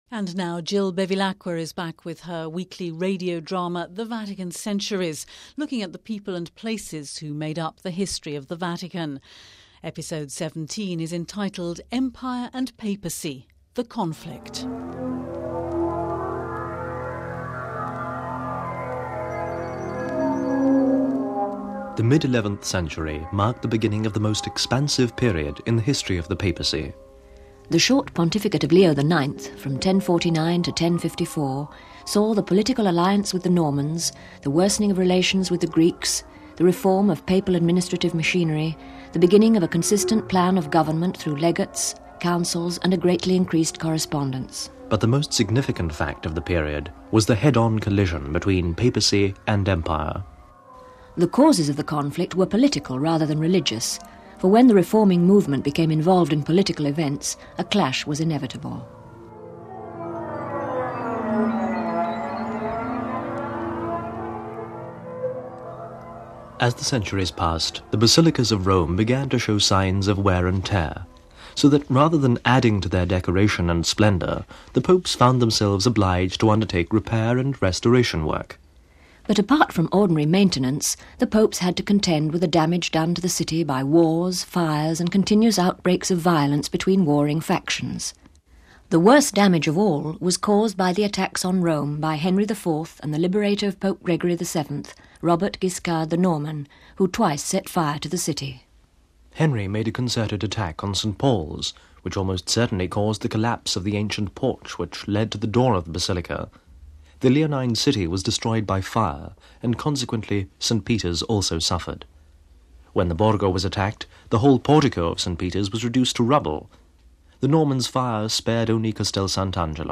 radio drama The Vatican Centuries